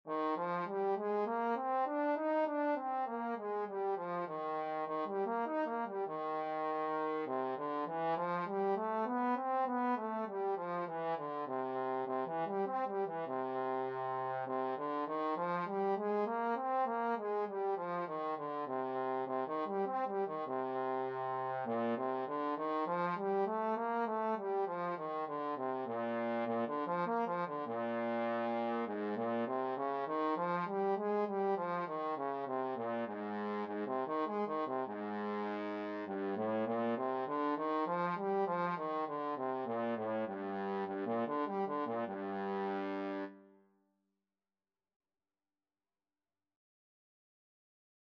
Trombone scales and arpeggios - Grade 2
Eb major (Sounding Pitch) (View more Eb major Music for Trombone )
4/4 (View more 4/4 Music)
G3-Eb5
trombone_scales_grade2.mp3